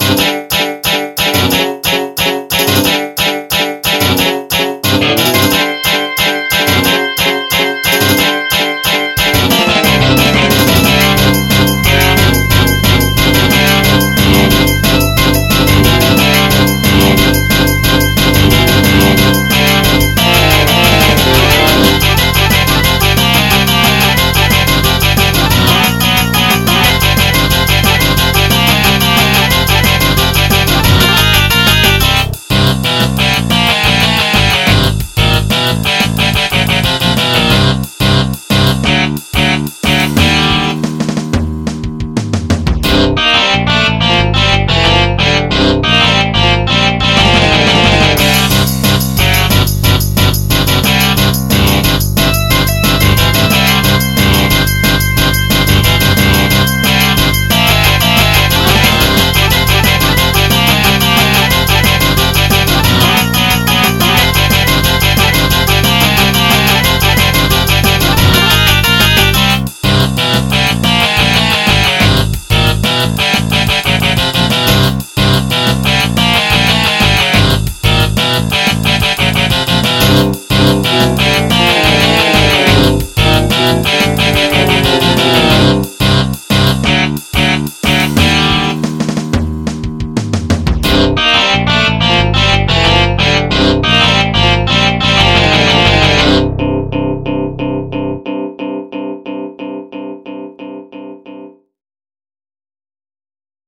MIDI 20.48 KB MP3
A Custom, and Fixed, MIDI file